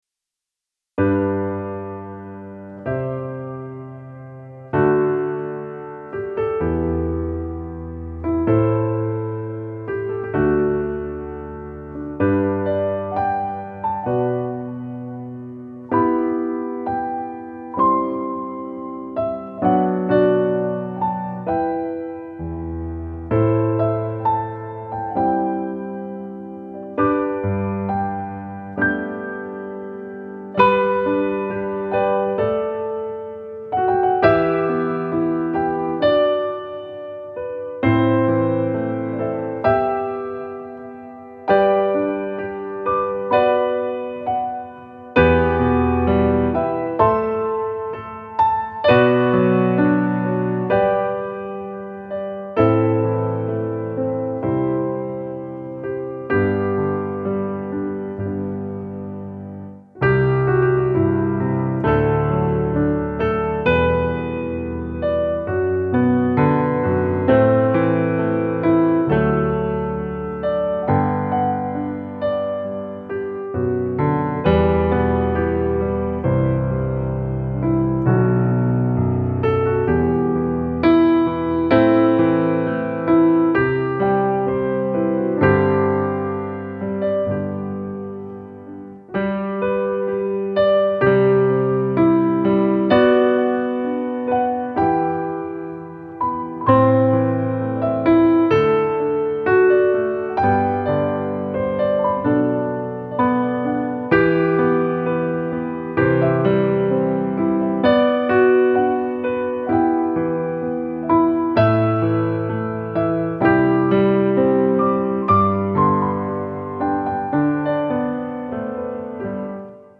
ピアノトラック・デモ
ゆったりとした曲調で綺麗なメロディラインが特徴のピアノ曲です。